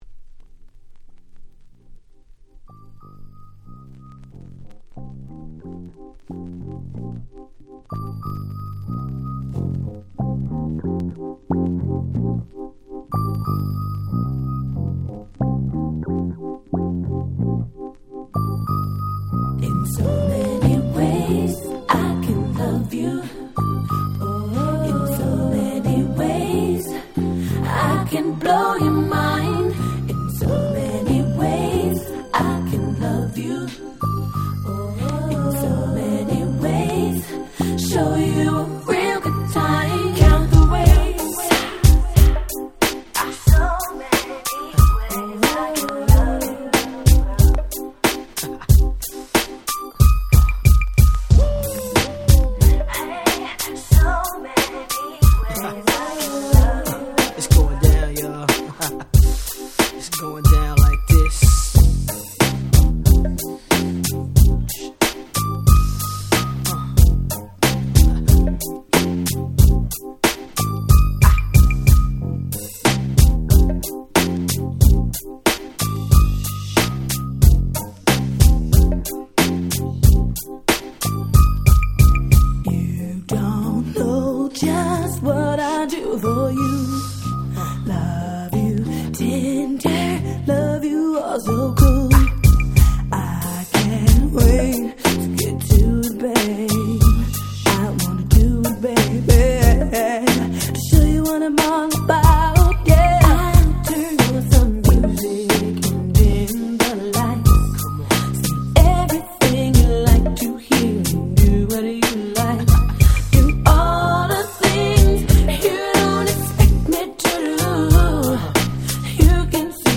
96' Smash Hit R&B !!
教科書通りのHip Hop Soulナンバー！
これぞ90's R&B !!